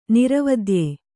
♪ niravadye